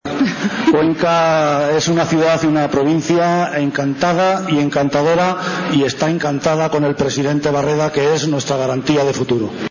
Alrededor de 6.000 personas han arropado hoy al presidente Barreda en el acto que los socialistas castellano-manchegos han celebrado en la localidad de Alcázar de san Juan (Ciudad Real).
El encuentro de los socialistas comenzó con las intervenciones de los secretarios generales del partido, que acompañados por las candidaturas autonómicas y de las principales localidades de la Región, respondieron a unas preguntas formuladas por una presentadora.